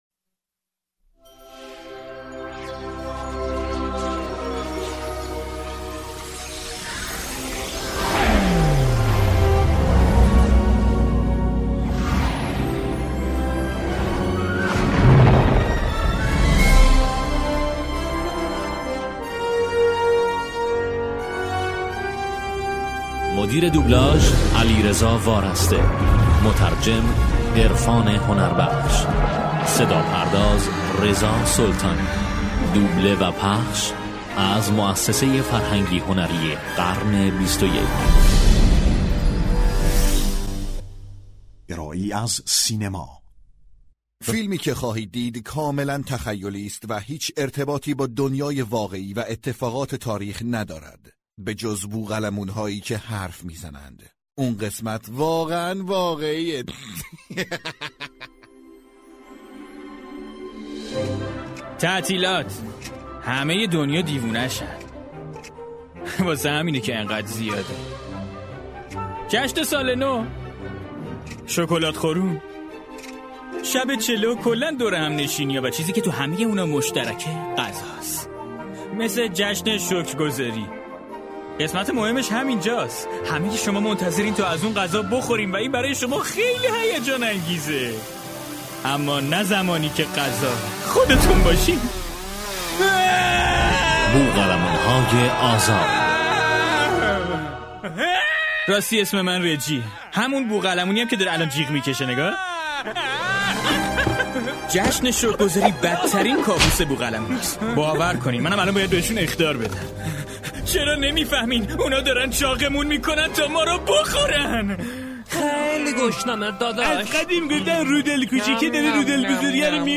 Free.Birds.2013.DUBBED.MP3.Exclusive.mka